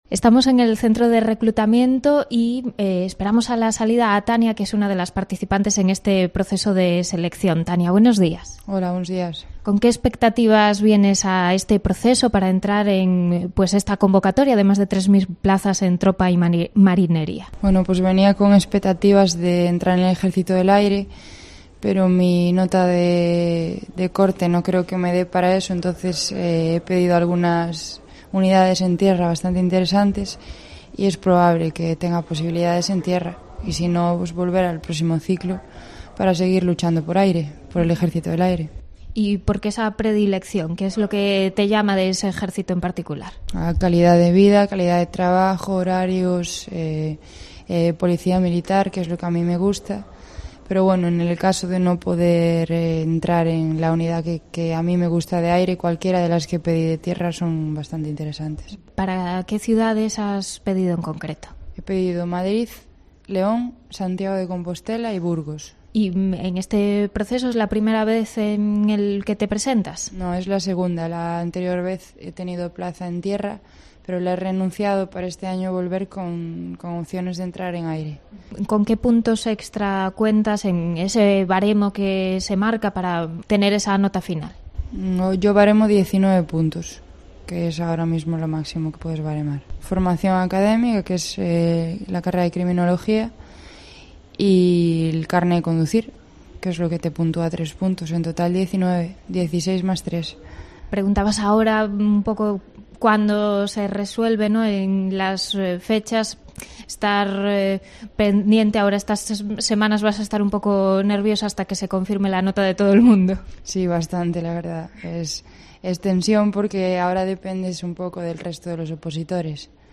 Testimonios de aspirantes a una plaza como militar de Tropa y Marinería